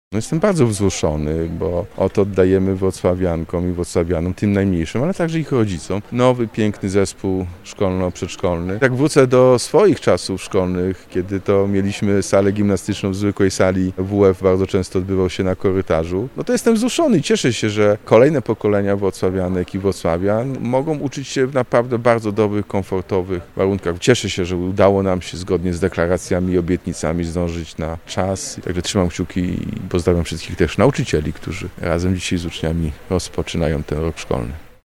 Prezydent Wrocławia Jacek Sutryk złożył życzenia wszystkim rozpoczynającym nowy rok szkolny.